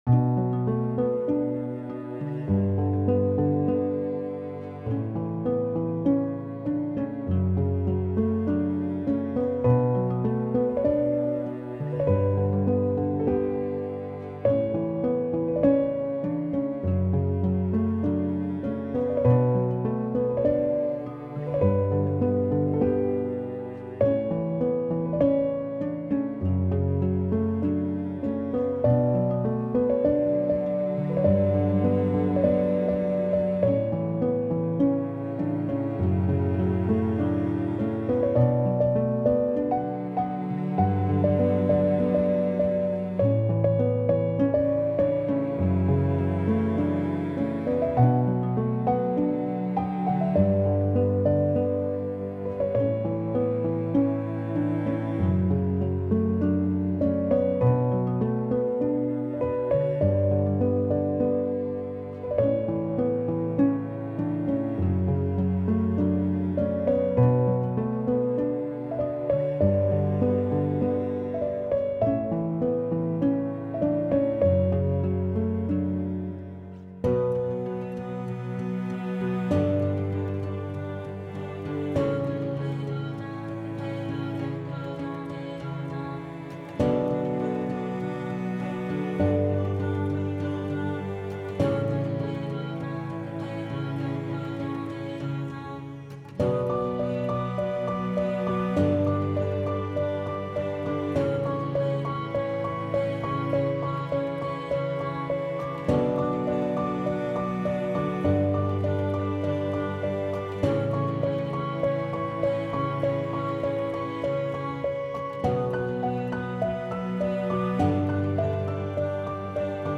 سبک آرامش بخش , پیانو , دیپ هاوس , موسیقی بی کلام
پیانو آرامبخش